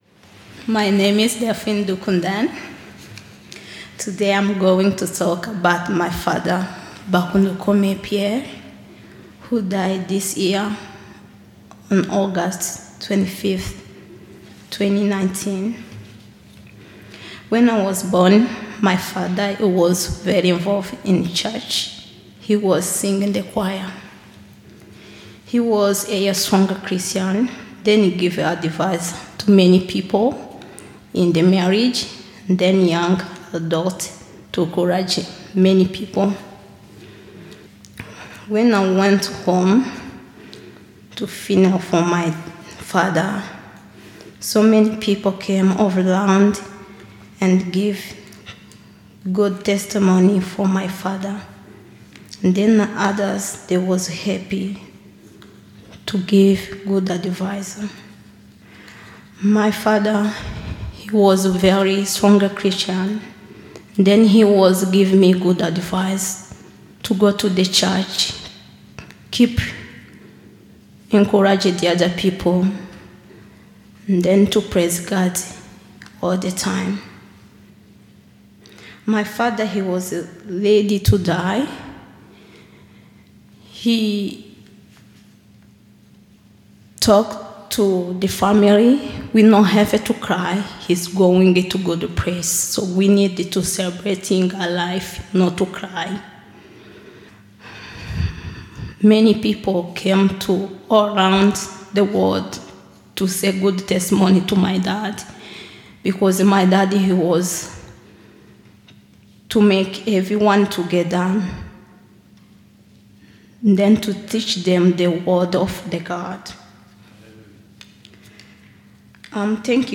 All Saints Testimony Service